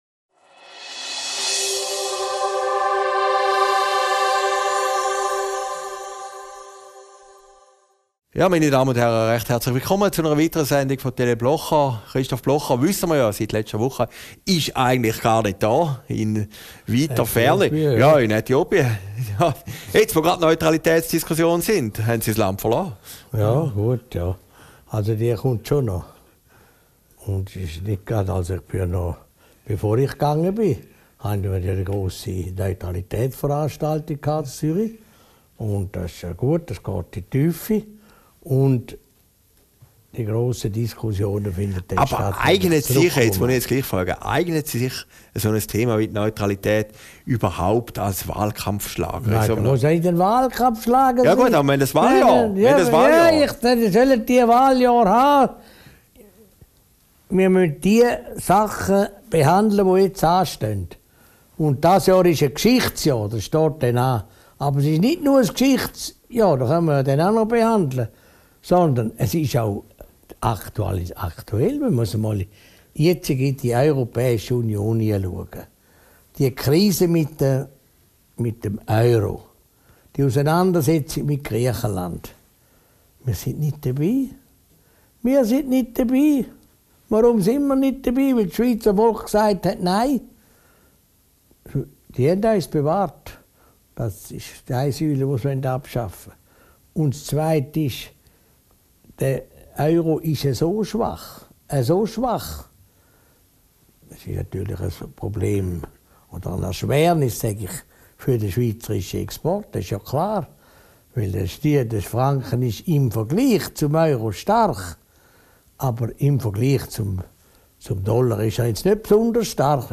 Video downloaden MP3 downloaden Christoph Blocher über Negativzinsen, Euroschock und Putins geheime Pläne Aufgezeichnet in Herrliberg, 18.